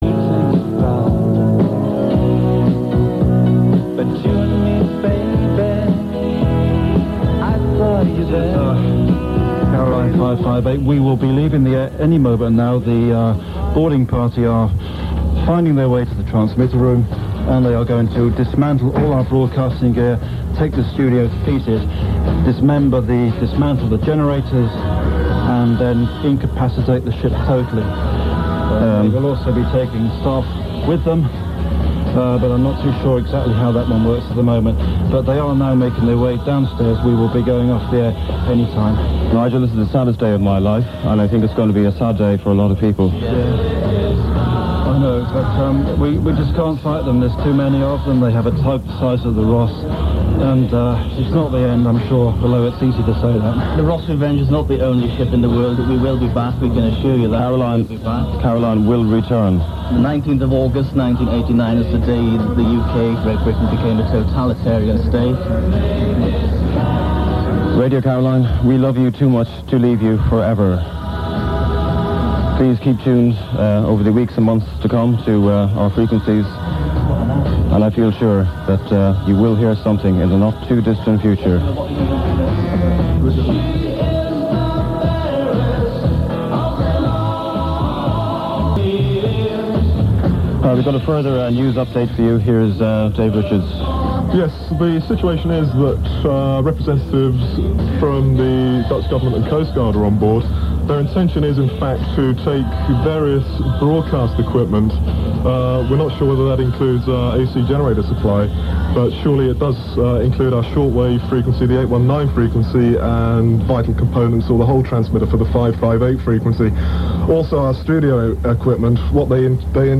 While all this was going on listeners to both stations were kept informed by way of special announcements, but at 1.08pm both medium wave transmitters on the Ross Revenge fell silent and once again Radio Caroline had gone off the airwaves.
7 cut off air 19.08.89.mp3